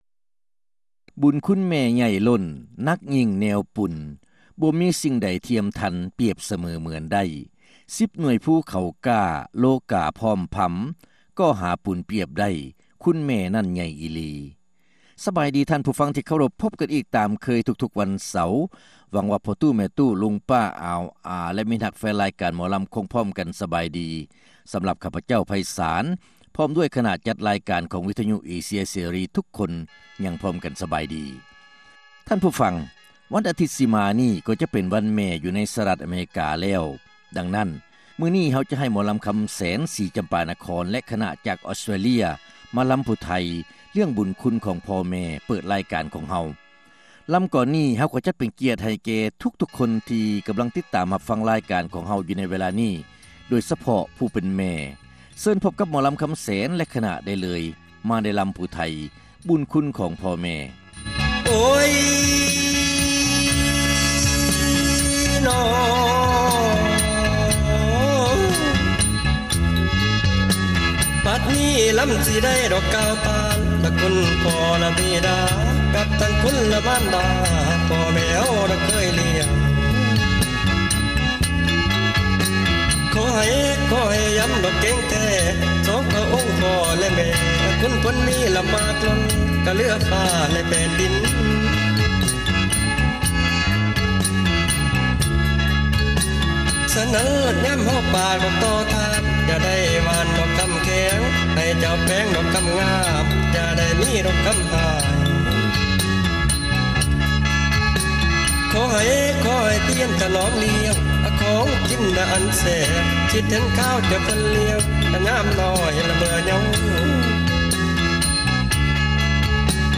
ຣາຍການໜໍລຳ ປະຈຳສັປະດາ ວັນທີ 12 ເດືອນ ພືສະພາ ປີ 2006